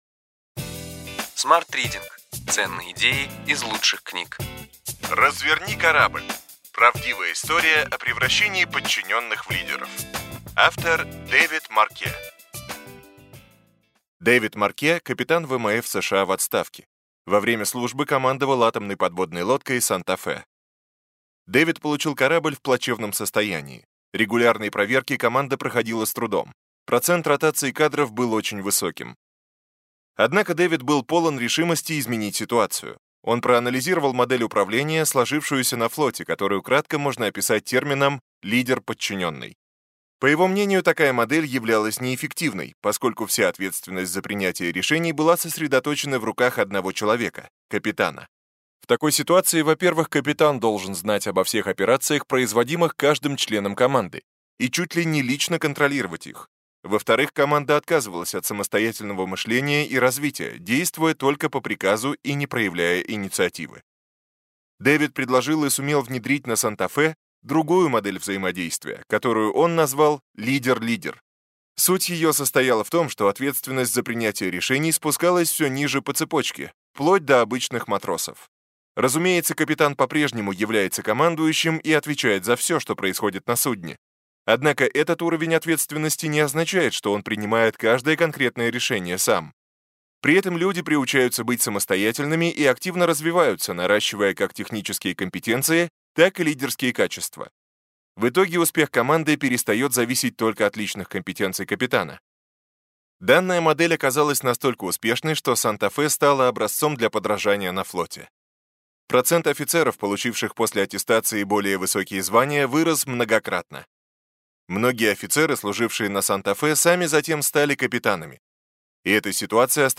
Аудиокнига Ключевые идеи книги: Разверни корабль! Правдивая история о превращении подчиненных в лидеров.